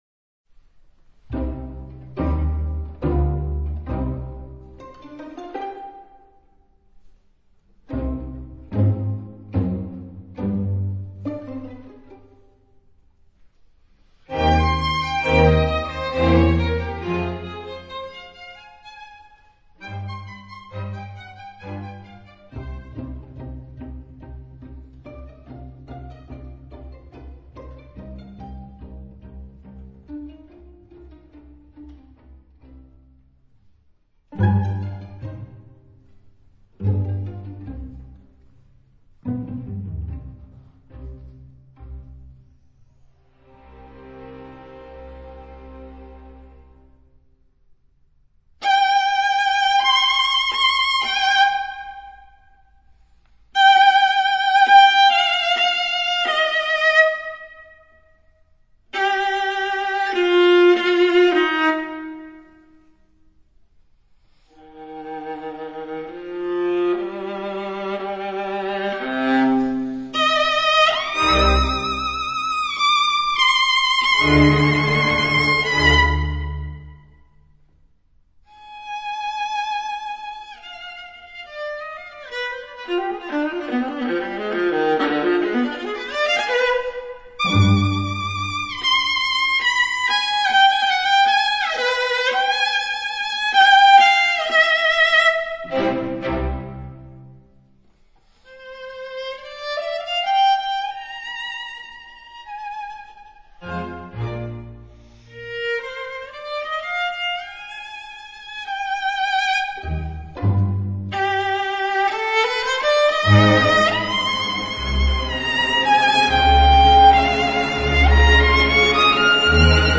這張中提琴的唱片，被樂友推薦為「有史以來最正確的錄音」。
毫無疑問，這是演奏與錄音都非常好的成果。